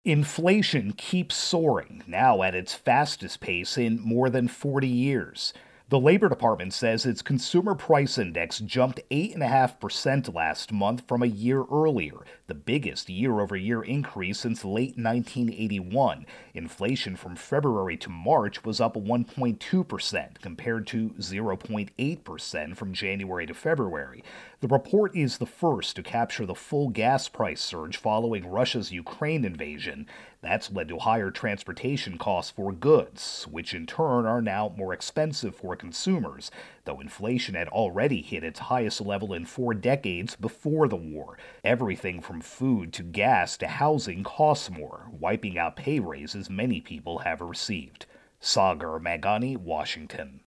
Consumer Prices intro and voicer.